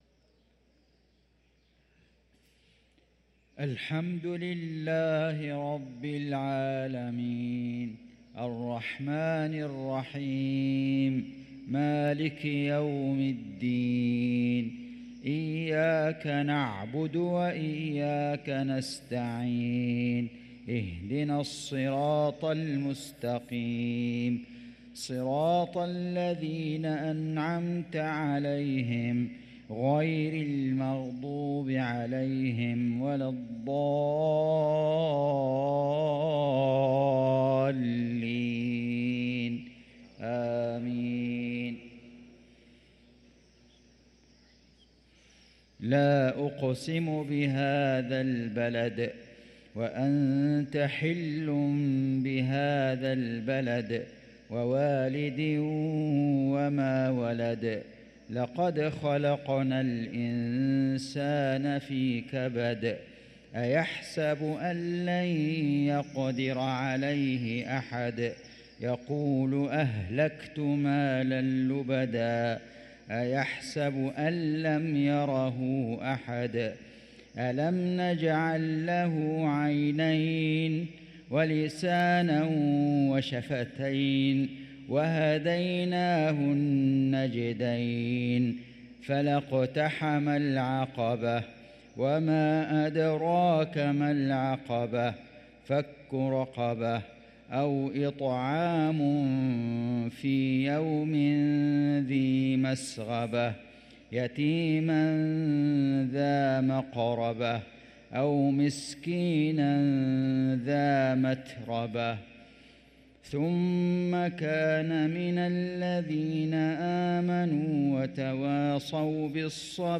صلاة المغرب للقارئ فيصل غزاوي 3 رجب 1445 هـ
تِلَاوَات الْحَرَمَيْن .